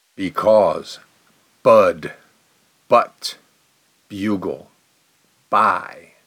Vowel-shifts_because.mp3